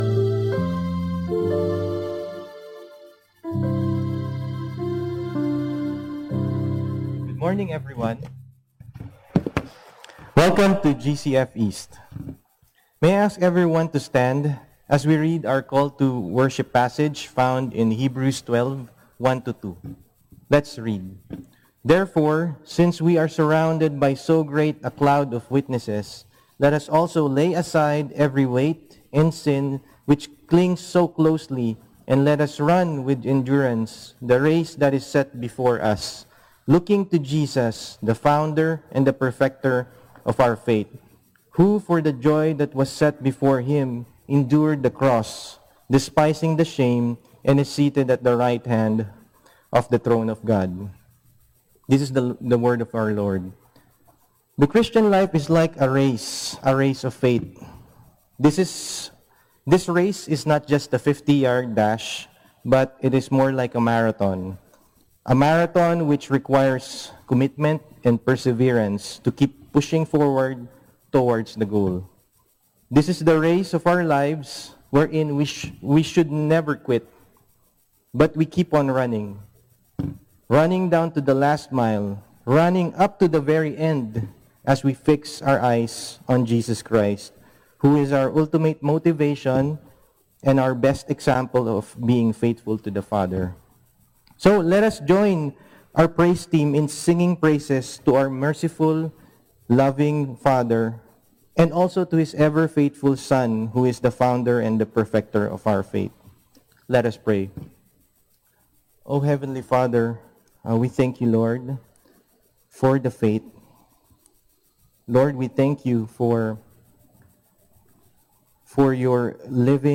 Service: Sunday